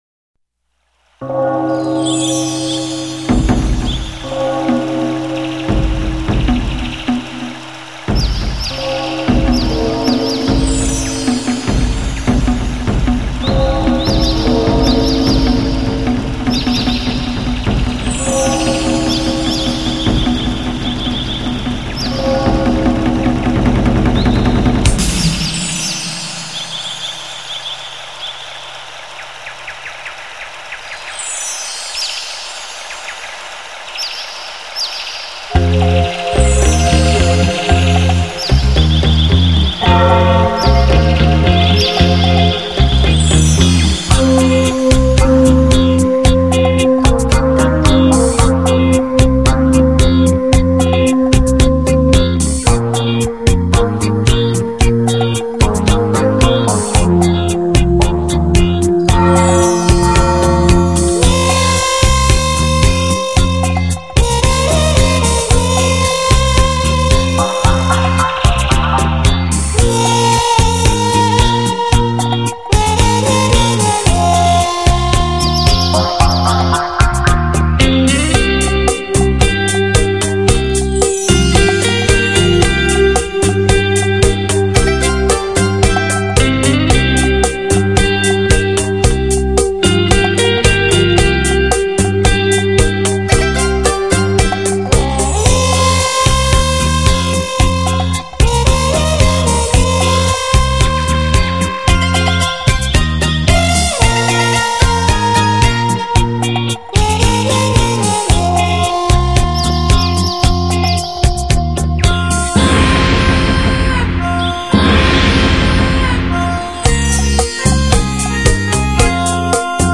撞击、枪战、尖叫声、邪恶的笑声、雨声、清脆的鼓点，等等音效，
穿插于一首首影视名曲之中，360度最佳临场感，突现了CD超凡的清澈音质！
强力震撼，小心您的音响喇叭！